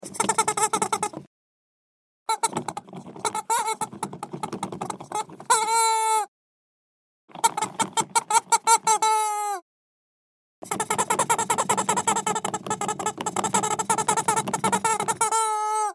دانلود صدای خروس عروسکی برای بچه ها و ساکت کردن آنها از ساعد نیوز با لینک مستقیم و کیفیت بالا
جلوه های صوتی